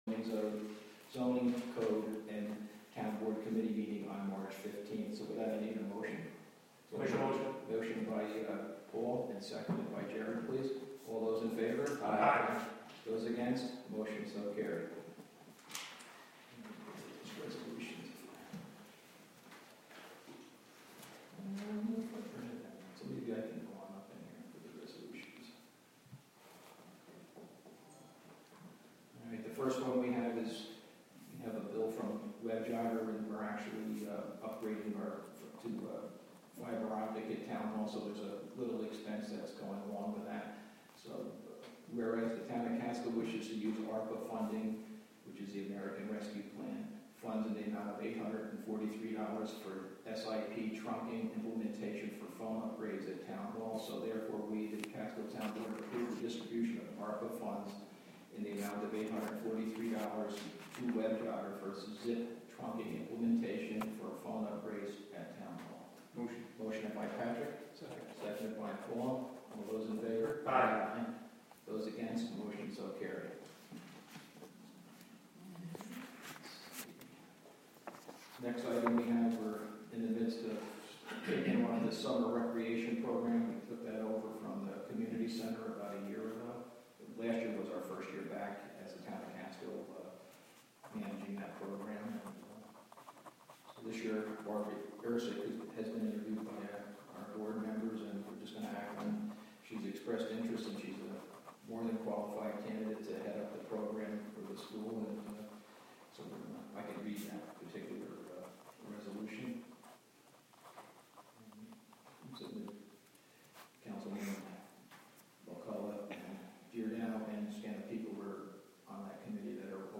The Town of Catskill holds their monthly meeting.
WGXC is partnering with the Town of Catskill to present live audio streams of public meetings.